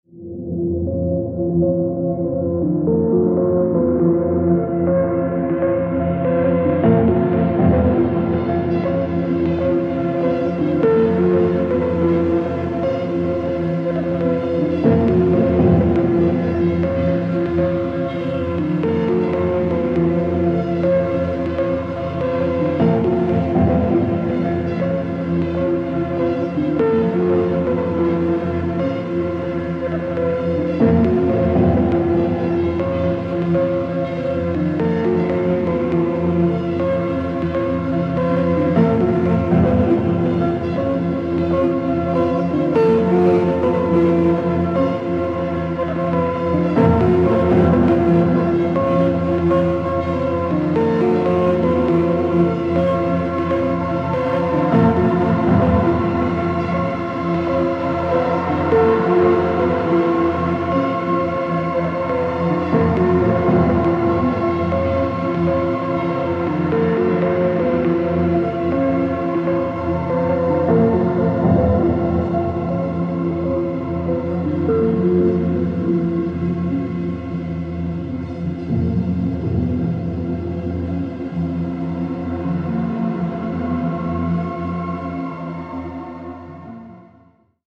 IDM/Electronica, Techno